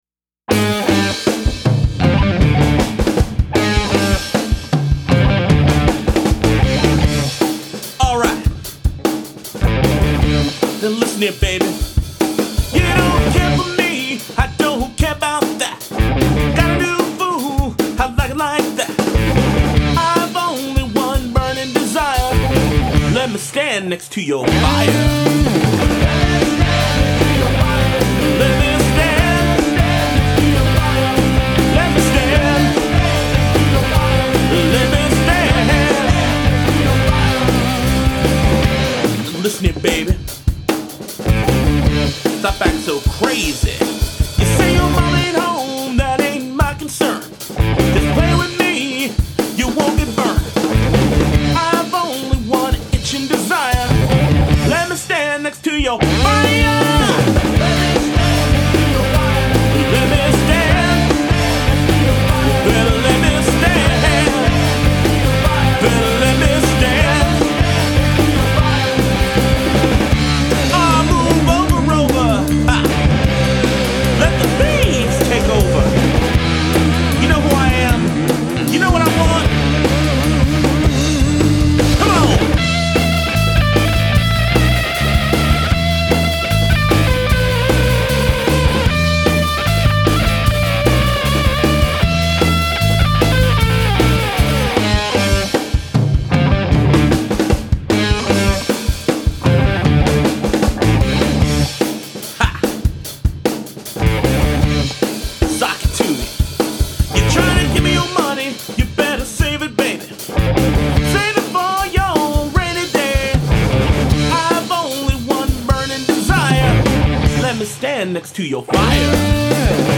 Guitars
Bass
Drums